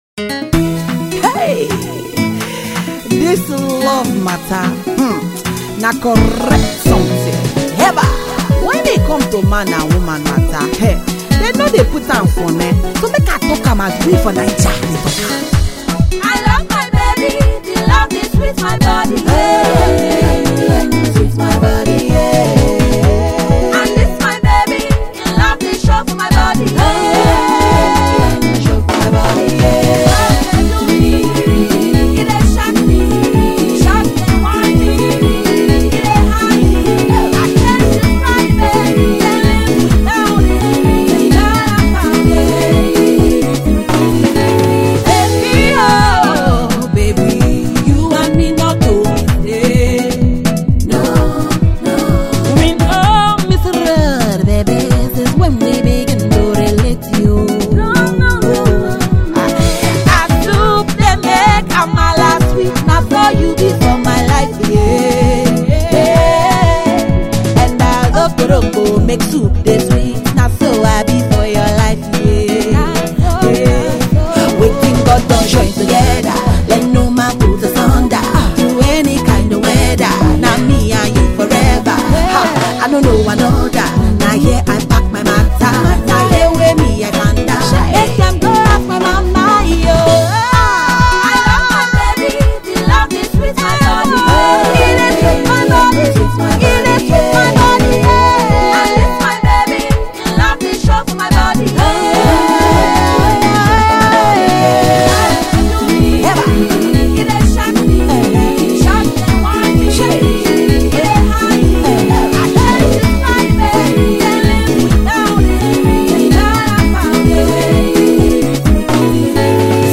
not many tell a story better than this Afro Soul Diva